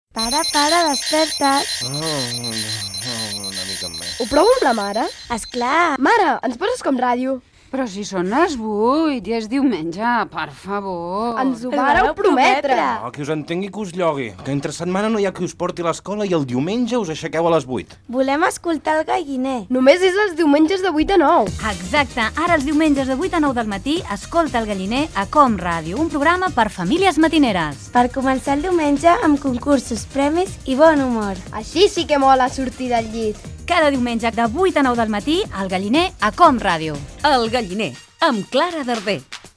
Promoció del programa
Infantil-juvenil